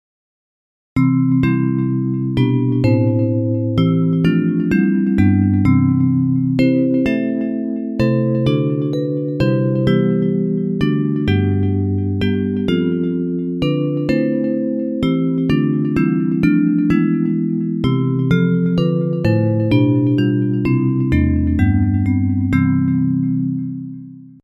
Bells